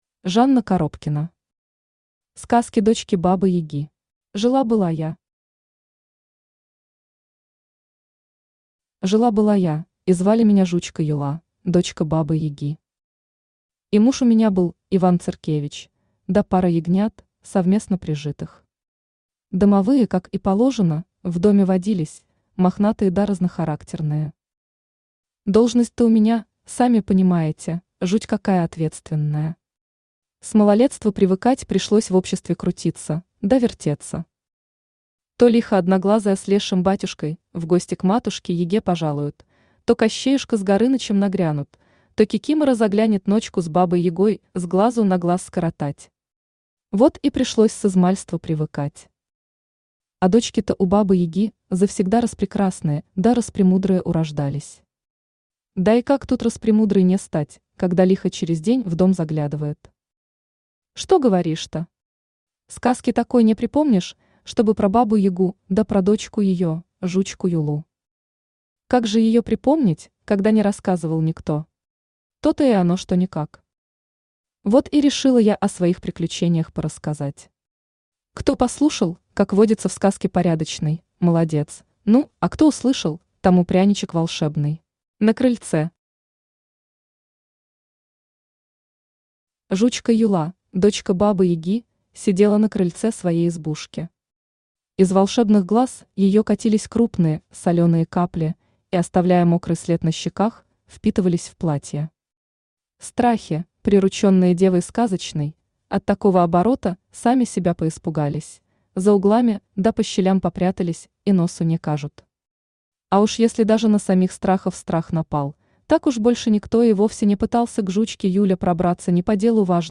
Аудиокнига Сказки дочки Бабы-Яги | Библиотека аудиокниг
Aудиокнига Сказки дочки Бабы-Яги Автор Жанна Коробкина Читает аудиокнигу Авточтец ЛитРес.